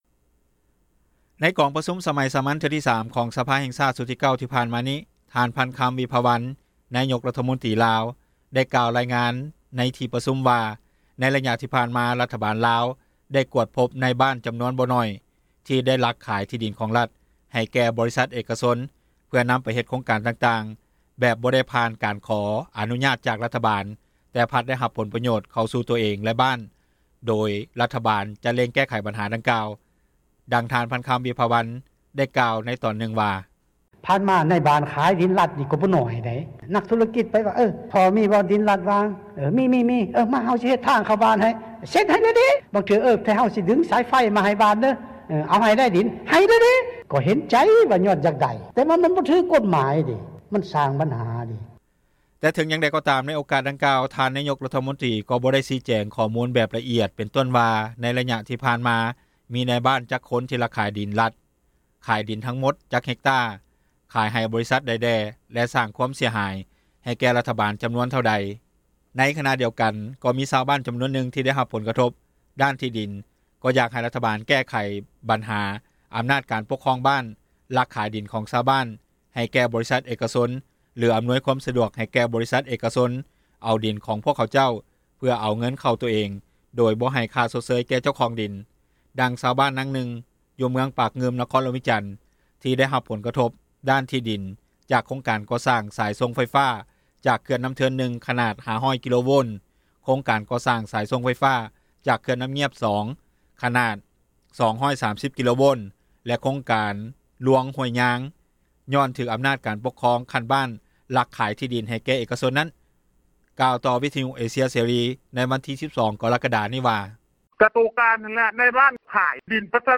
ໃນກອງປະຊຸມສມັຍສາມັນເທື່ອທີ 3 ຂອງສະພາແຫ່ງຊາຕຊຸດທີ 9 ທີ່ຜ່ານມານີ້ ທ່ານ ພັນຄຳ ວິພາວັນ ນາຍົກຣັຖມົນຕຣີ ໄດ້ກ່າວຣາຍງານໃນທີ່ປະຊຸມວ່າ ໃນໄລຍະຜ່ານມາ ຣັຖບານໄດ້ກວດພົບນາຍບ້ານຈຳນວນບໍ່ໜ້ອຍທີ່ໄດ້ລັກຂາຍທີ່ດິນ ຂອງຣັຖໃຫ້ແກ່ບໍຣິສັດເອກຊົນ ເພື່ອນຳໄປເຮັດໂຄງການຕ່າງໆ ແບບບໍ່ໄດ້ຜ່ານການຂໍອະນຸຍາດ ຈາກຣັຖບານແຕ່ພັດໄດ້ຮັບຜົລປໂຍດ ເຂົ້າສູ່ໂຕເອງແລະບ້ານ ໂດຍຣັຖບານຈະເລັ່ງແກ້ໄຂບັນຫາດັ່ງກ່າວ.
ດັ່ງ ທ່ານ ພັນຄຳ ວິພາວັນ ໄດ້ກ່າວບາງຕອນຣະບຸວ່າ: